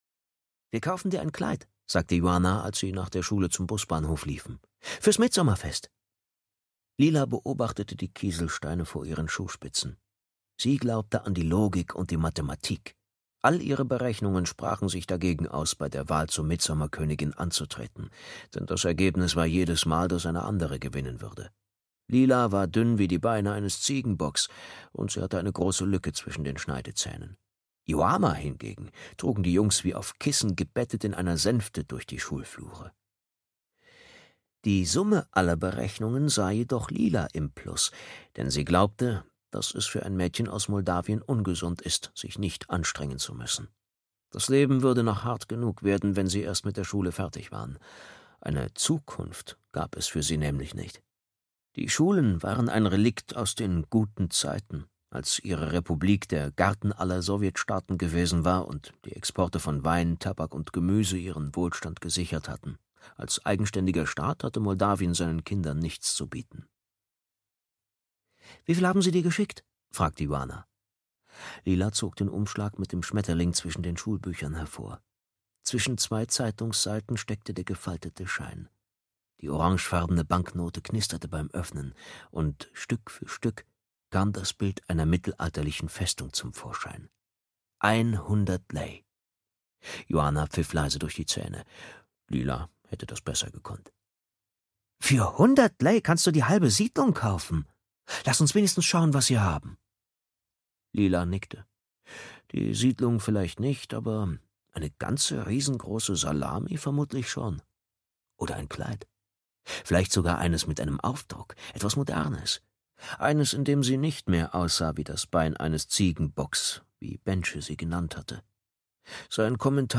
Argwohn (Solveigh Lang-Reihe 3) - Jenk Saborowski - Hörbuch